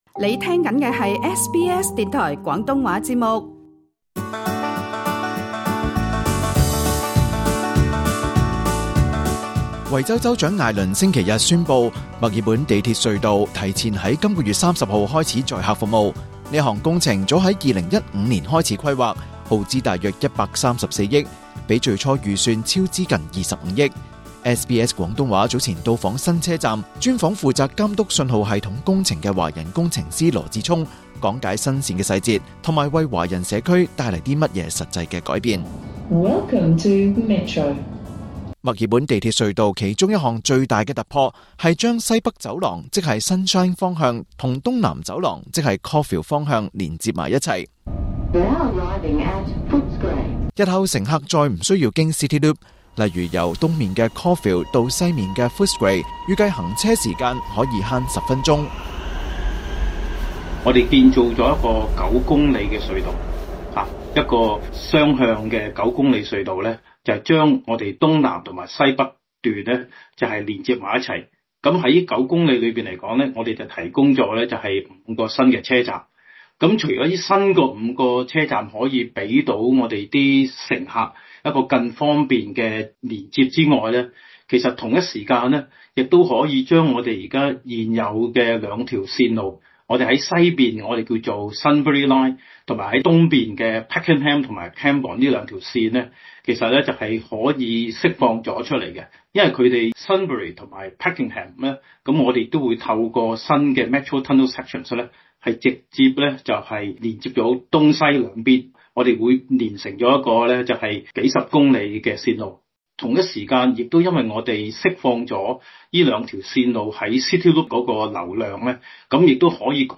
墨爾本地鐵隧道宣布提早通車 專訪華人工程師拆解新綫工程細節